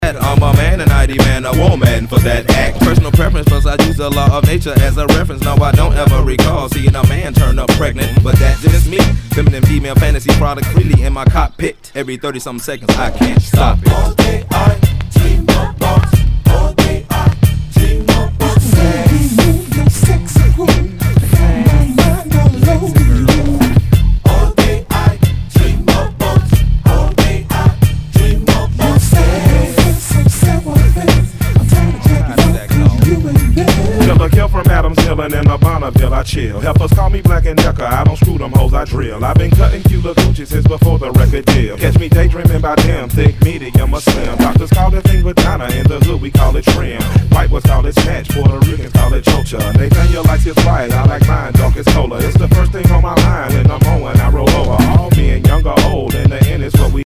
HIPHOP/R&B